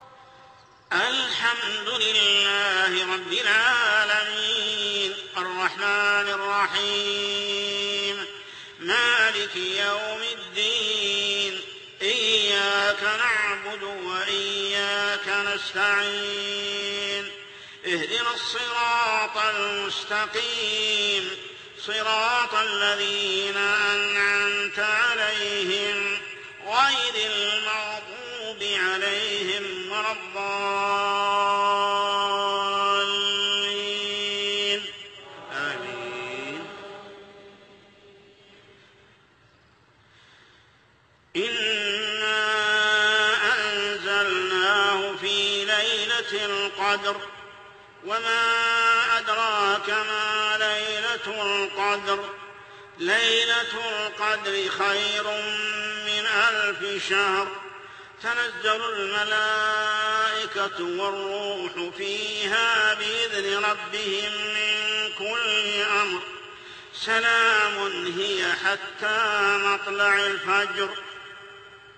صلاة العشاء الركعة الثانية عام 1428هـ سورة القدر كاملة | Isha prayer surah al-qadr > 1428 🕋 > الفروض - تلاوات الحرمين